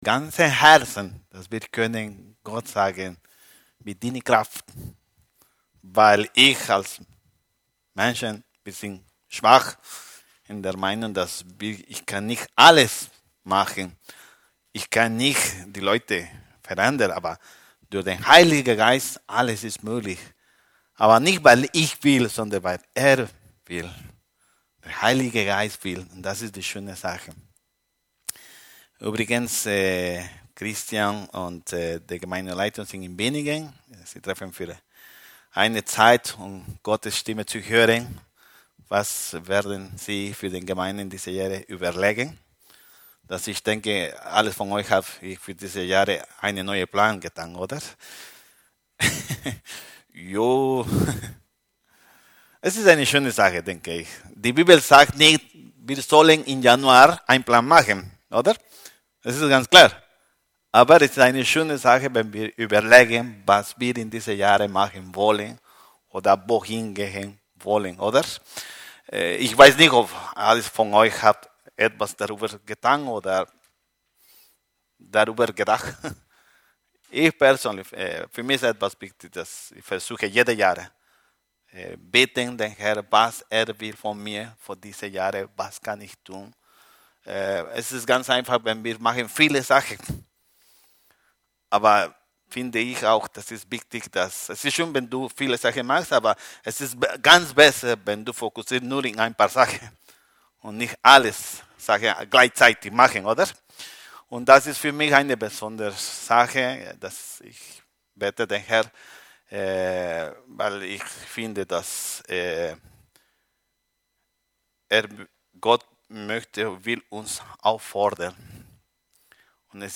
Der Predigt heute ist über das Thema: Heiliger Geist, sprich zu mir. Er will uns neuen Sachen zeigen, damit wir evangelisieren können.